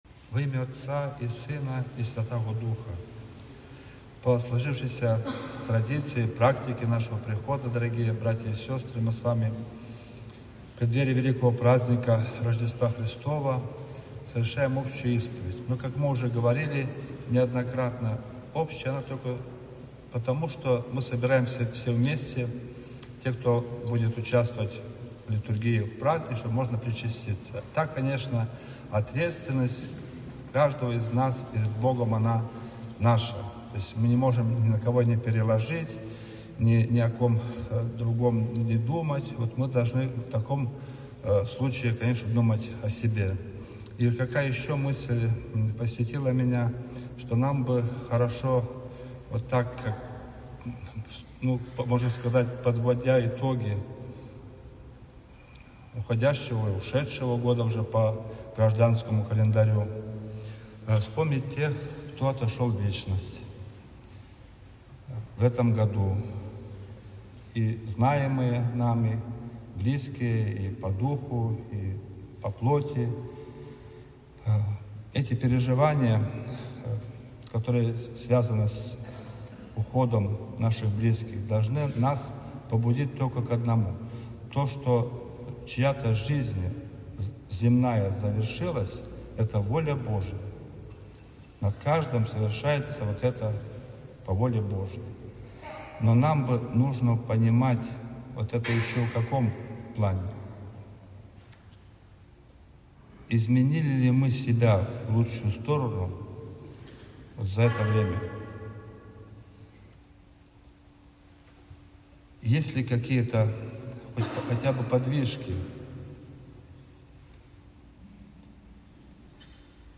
По сложившейся приходской традиции после богослужения была совершена общая исповедь.
Общая-исповедь.mp3